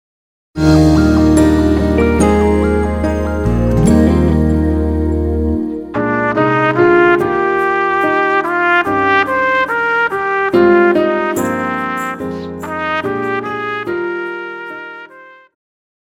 Pop,Christian
Trumpet
Band
Traditional (Folk),POP
Instrumental
Only backing